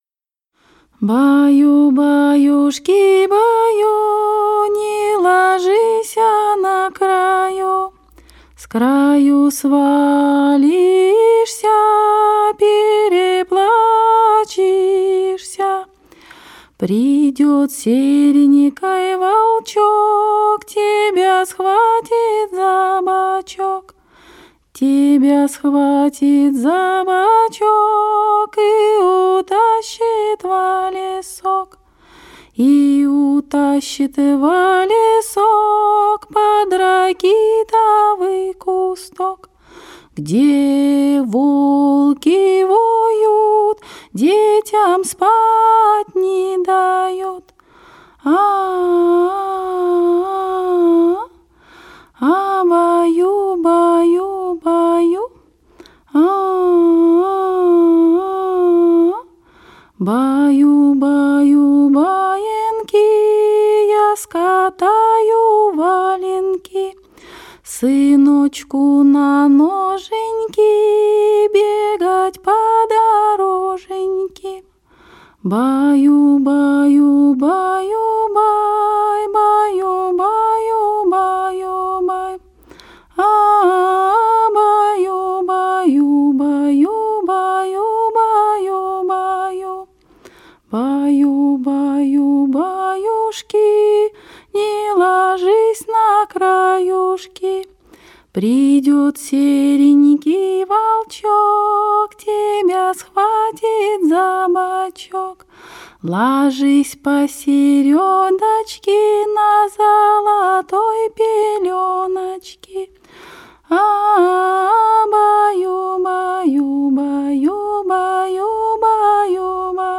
Колыбельные